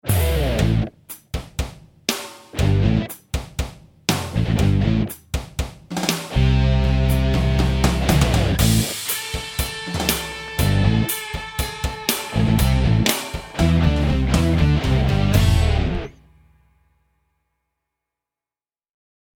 featuring the VG-99